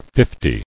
[fif·ty]